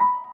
piano_last04.ogg